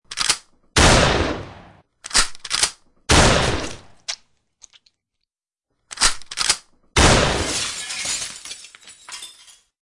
gun_shot.wav